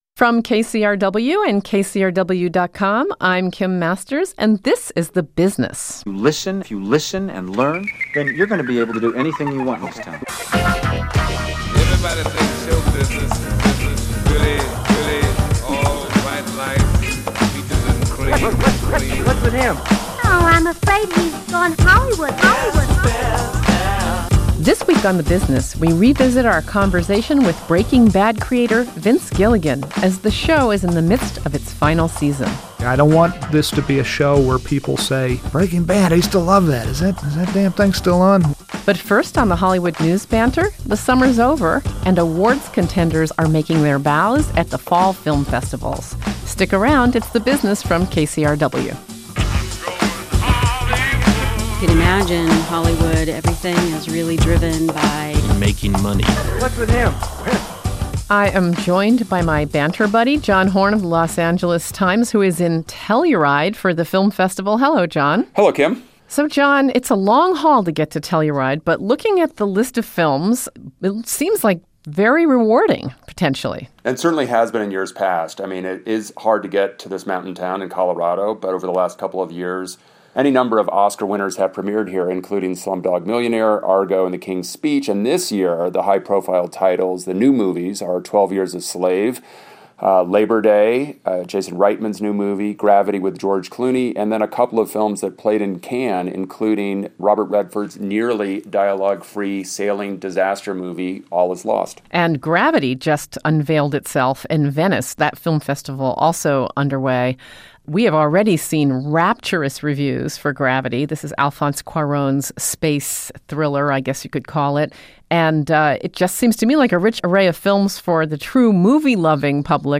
As the AMC series Breaking Bad reaches its final episodes, we revisit our conversation with creator Vince Gilligan.